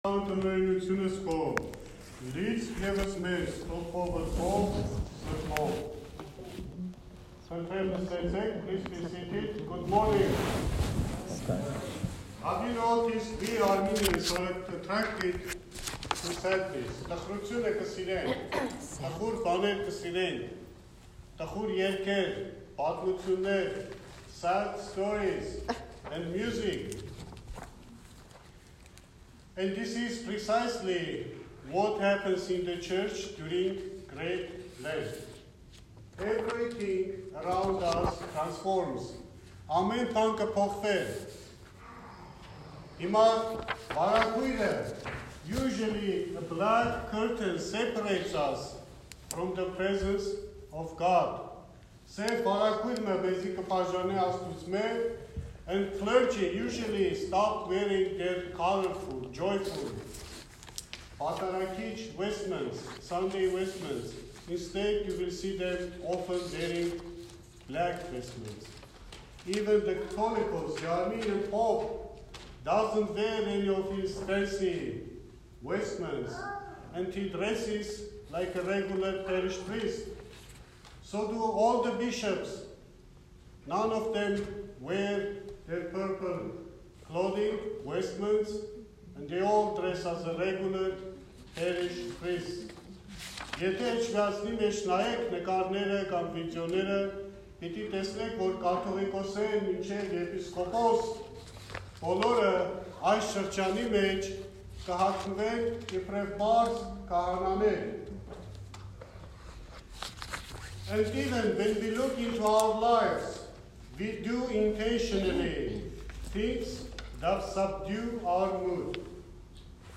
Sermon – Lent: A Season of Bright Sadness